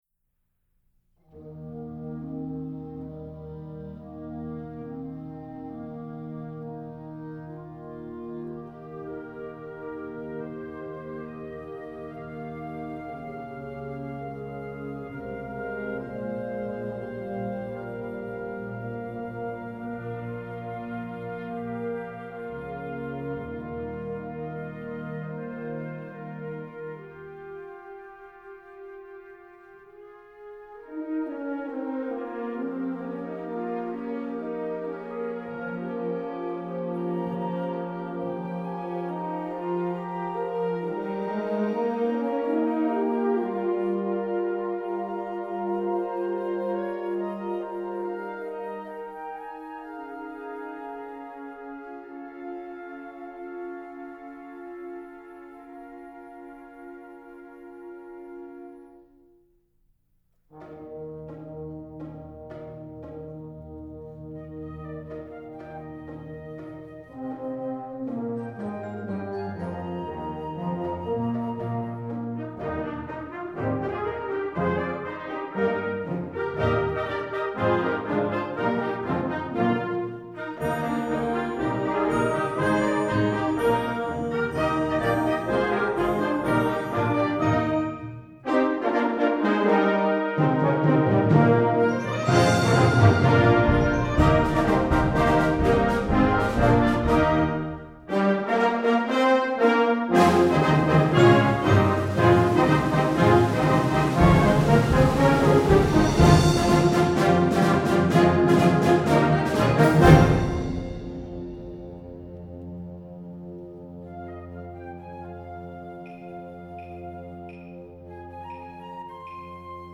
Genre: Band
Piccolo
Tuba
Mallet Percussion [3 Players] (Bells, Vibraphone, Xylophone)
Timpani (4)